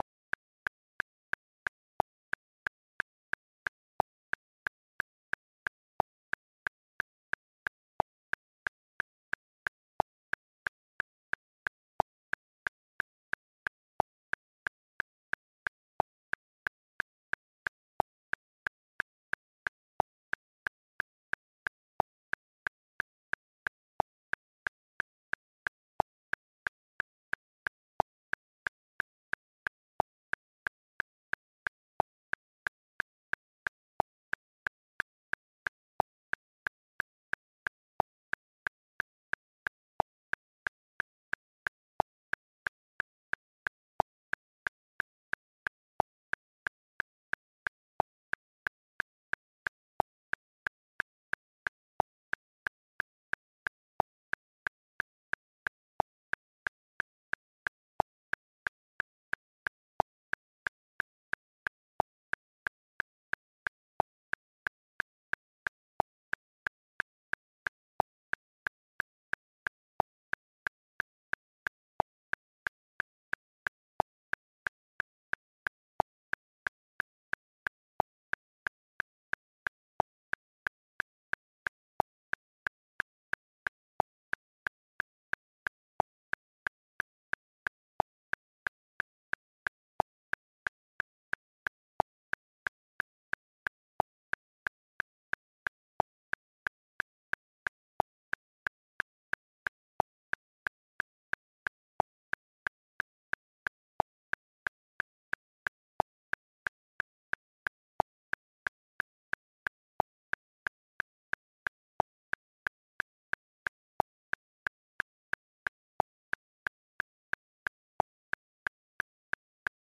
AUDITION - Drummer - Heavy sludge riff - Need heavy drums - Collectiv
Looking for a drummer who can provide heavy drums for a progressive sludge/doom song I'm working on.
Full credit will be given. 6/8 timing.